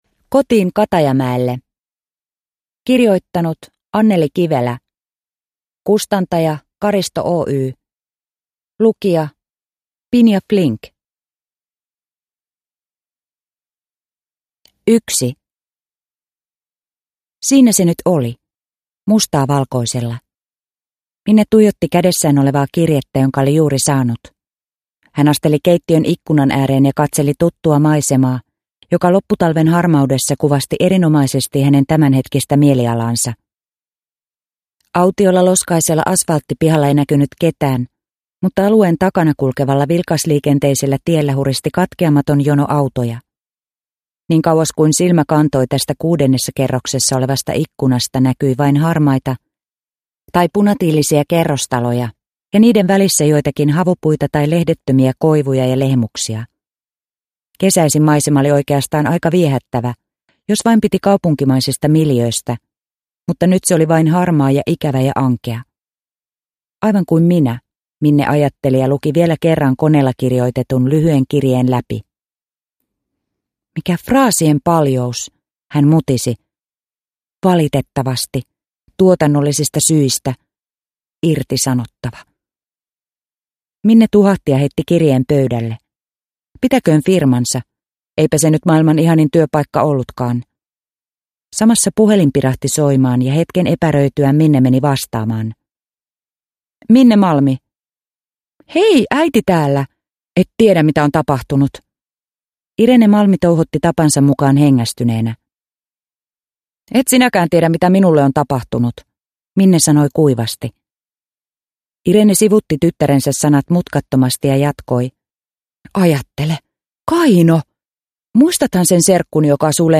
Kotiin Katajamäelle – Ljudbok – Laddas ner